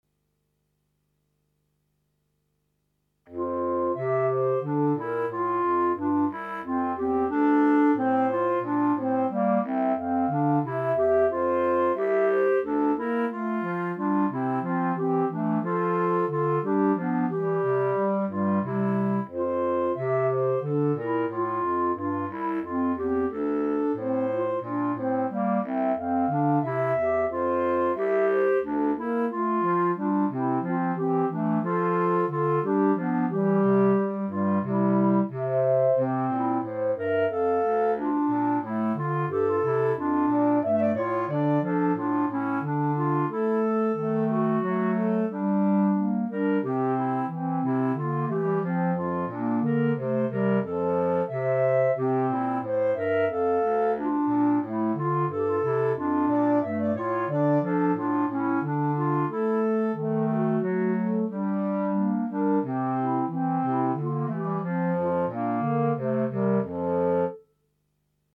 Right click to download Hornpipe minus Clarinet 1